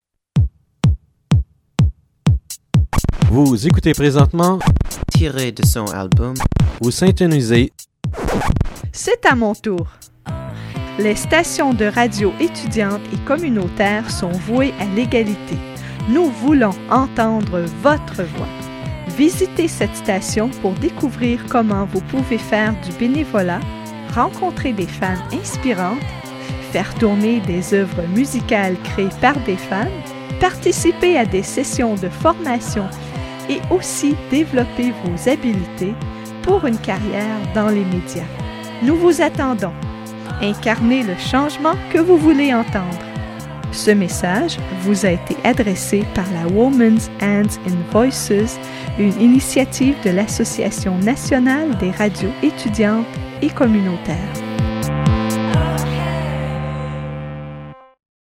MP3s of recruitment ads for women